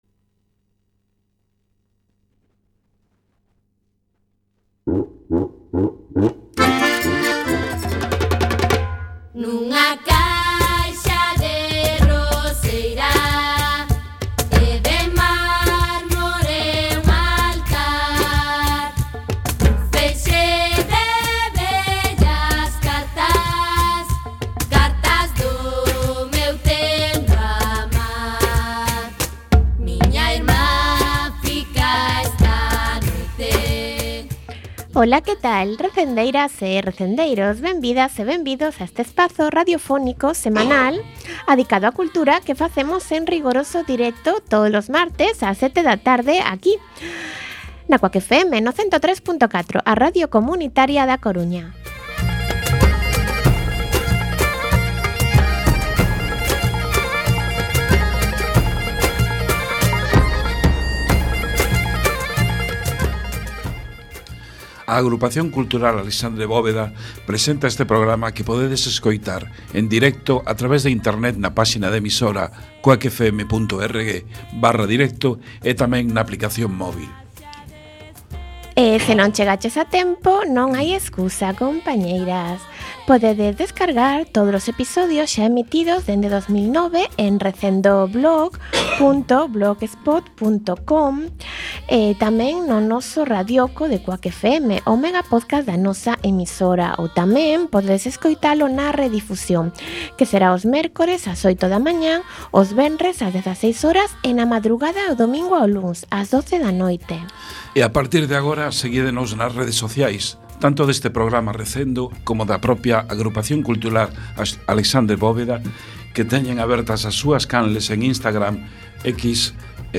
Programa número 516, no que entrevistamos a Henrique Monteagudo, novo presidente da Real Academia Galega.